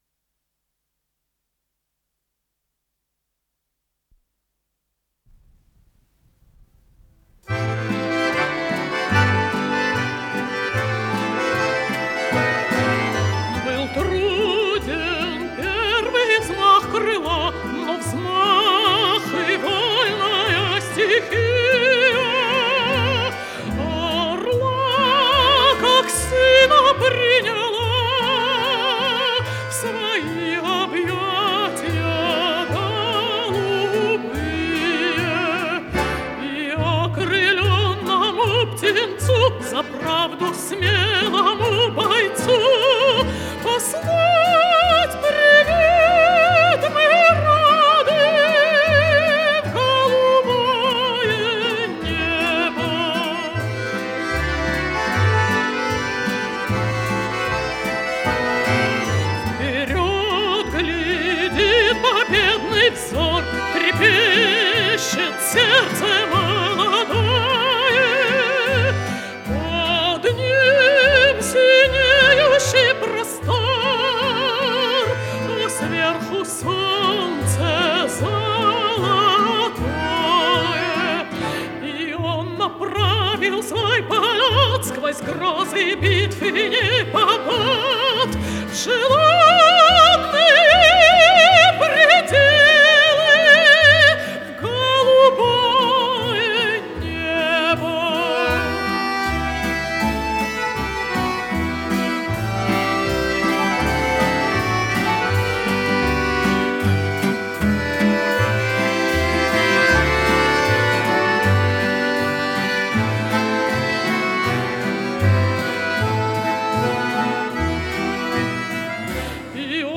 с профессиональной магнитной ленты
меццо-сопрано
ВариантДубль моно